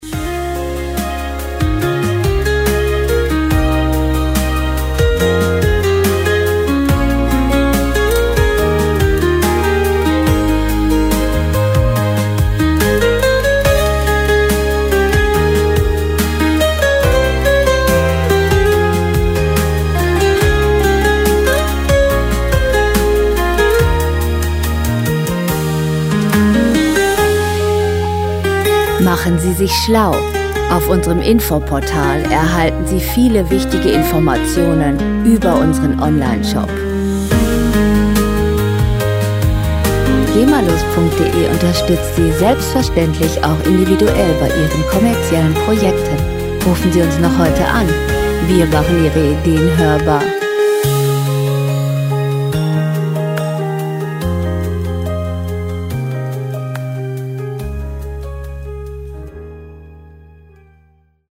Werbemusik - Lifestyle
Musikstil: Easy Listening
Tempo: 70 bpm
Tonart: As-Dur
Charakter: weich, leicht
Instrumentierung: E-Gitarre, E-Bass, Drums, Strings, Orgel